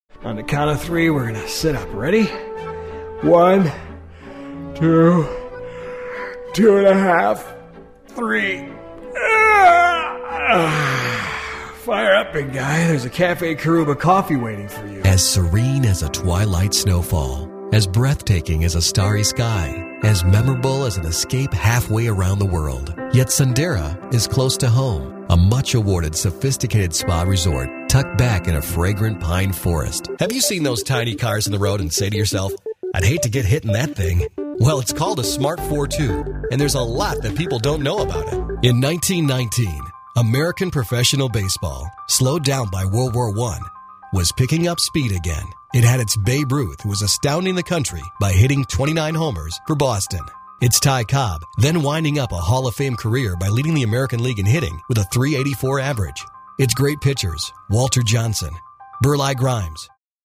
Male Voice Talent